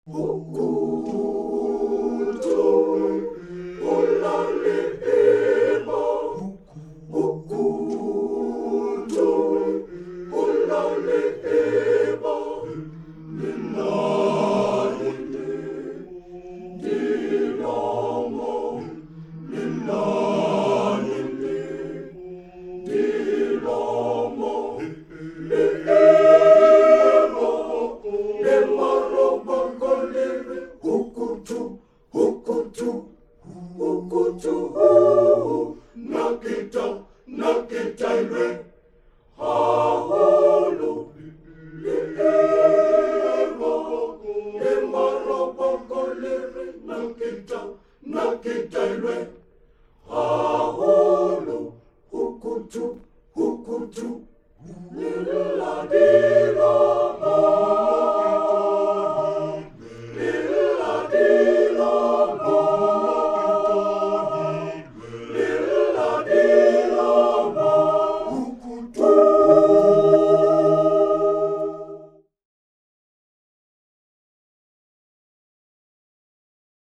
drowsy 1939 song
Type: Studio Recording Performers